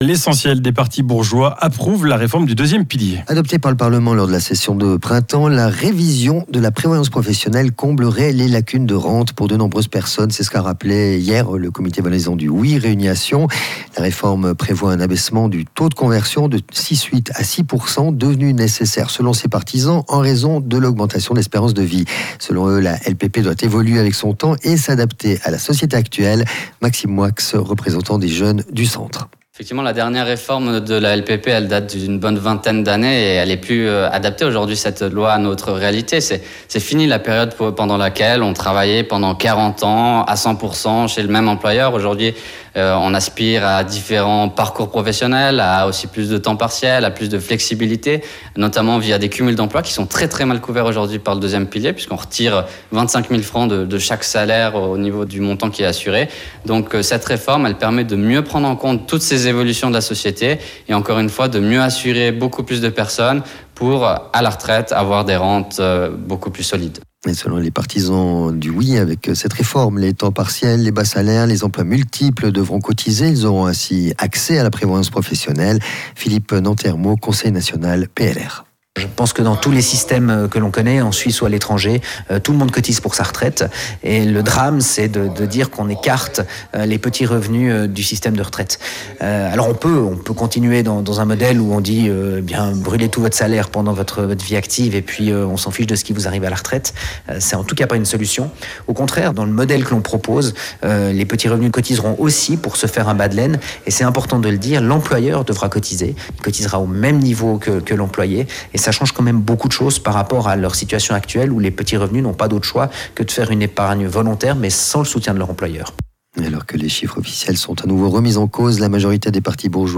03.09.2024 - Rhône FM (audio/interview) : Réforme LPP : en Valais, l'essentiel des partis bourgeois appellent à voter OUI